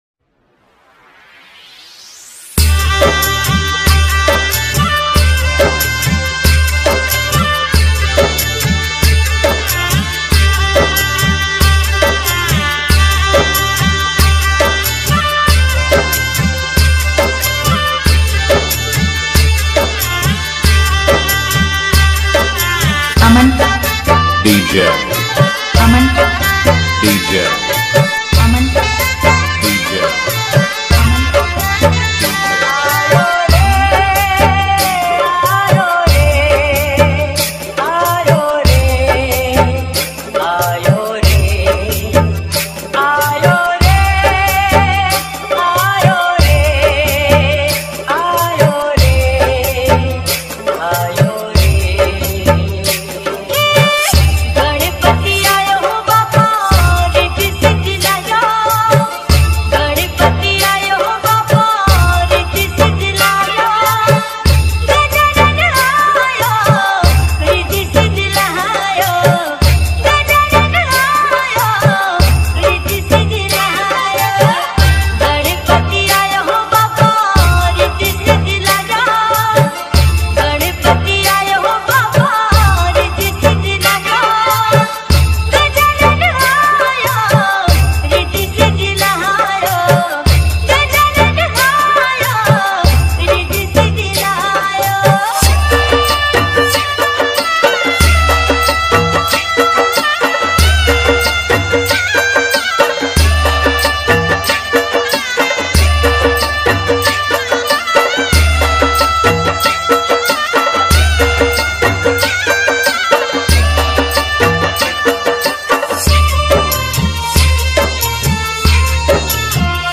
Ganesh Chaturthi Dj Remix Song
High Bass Ganpati Remix